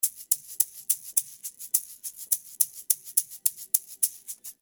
105 Bpm Egg Shaker (5 variations)
5 loops of egg shaker playing at105 bpm
This shaker plays a style called " baladi " , if you are using the loops on arabic baladi style ,this shaker will do a great job.